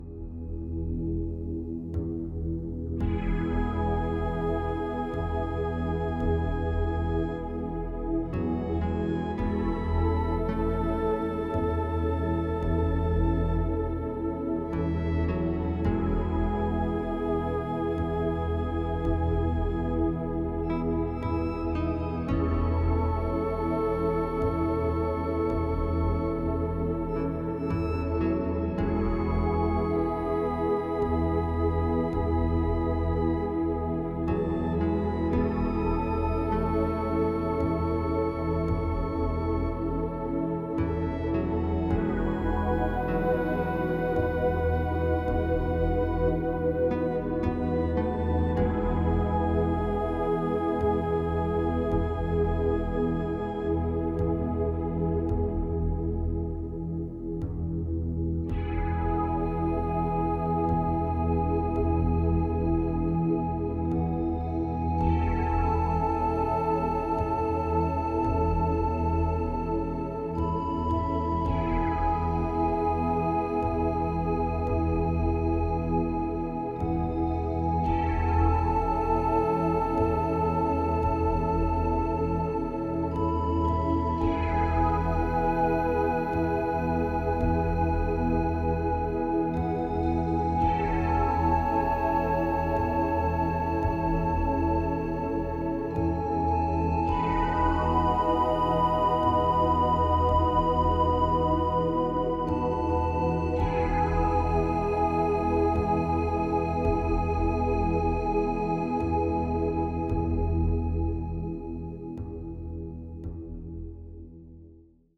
heel ontspannend, met nogal wat natuurgeluiden